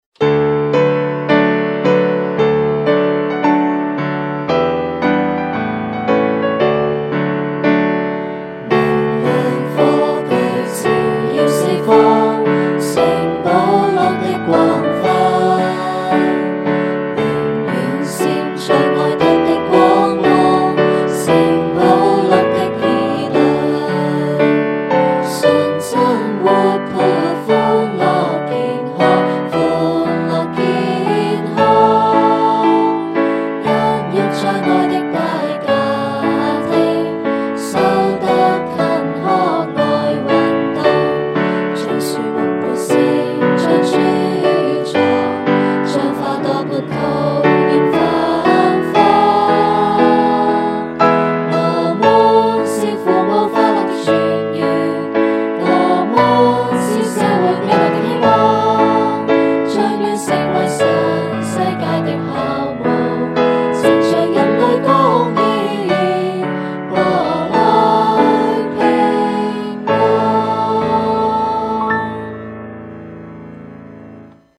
校歌 School Anthem
校歌-2018版人聲(中學).mp3